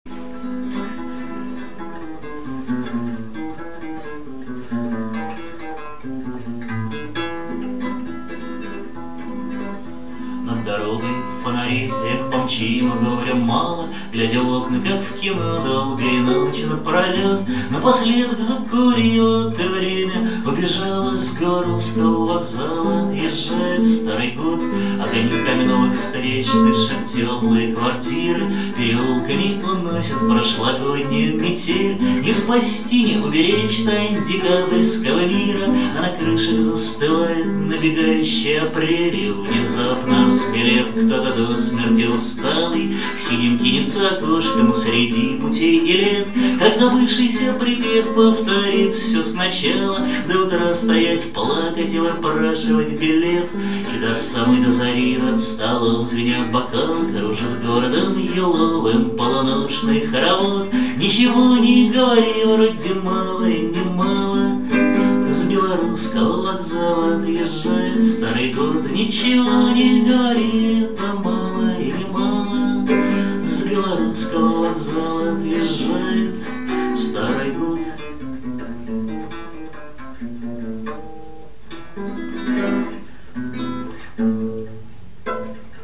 Запись первых трёх часов "Праздника Самой Длинной Ночи" в ЦАПе 22.12.2002